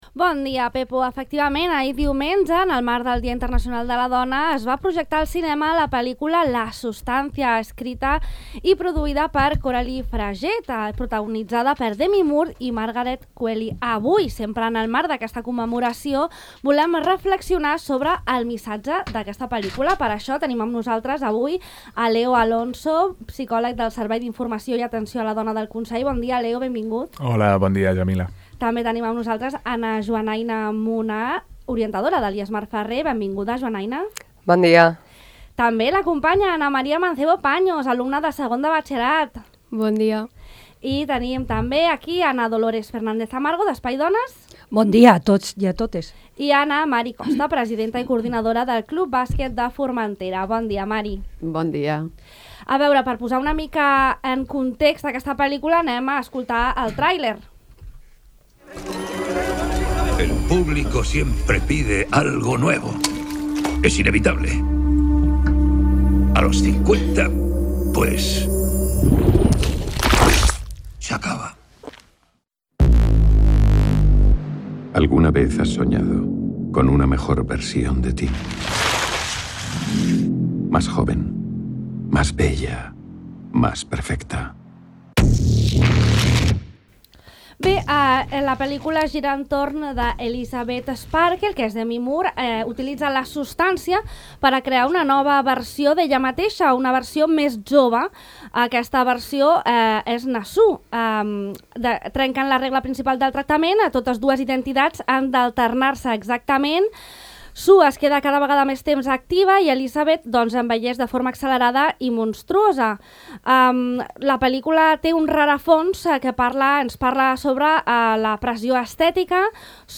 Taula Rodona sobre ‘La Substància’.